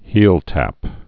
(hēltăp)